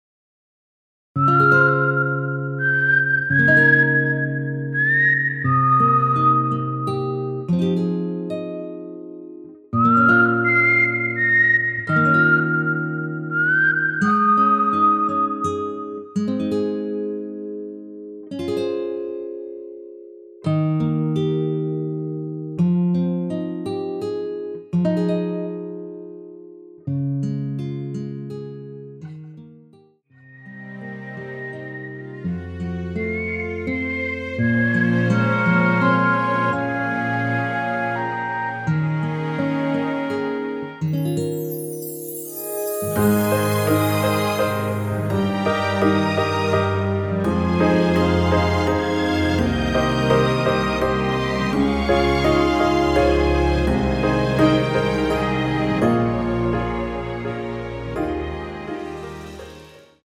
무반주 구간 들어가는 부분과 박자 맞출수 있게 쉐이커로 박자 넣어 놓았습니다.(일반 MR 미리듣기 참조)
Ab
앞부분30초, 뒷부분30초씩 편집해서 올려 드리고 있습니다.